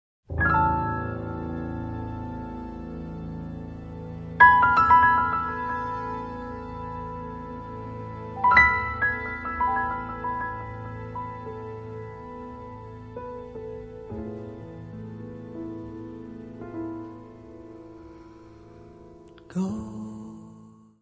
voz
acordeão diatónico
clarinete, saxofone tenor, saxofone soprano
contrabaixo
Music Category/Genre:  World and Traditional Music